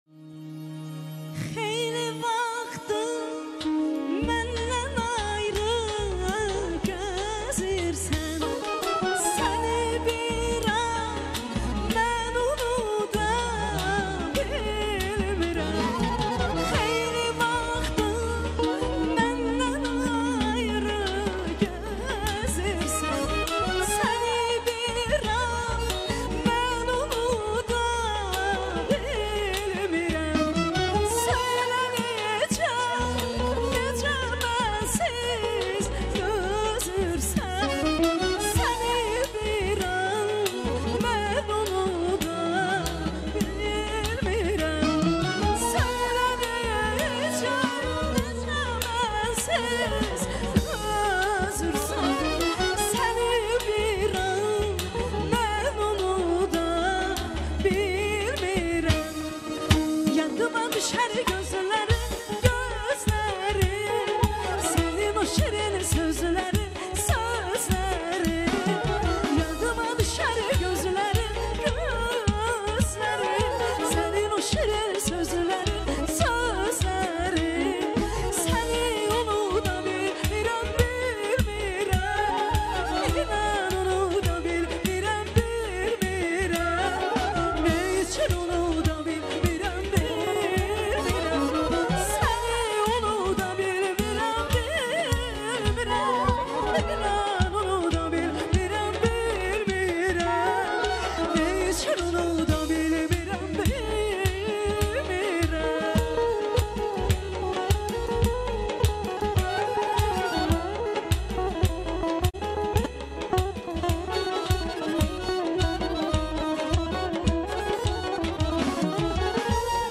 CANLI İFA